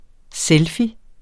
Udtale [ ˈsεlfi ]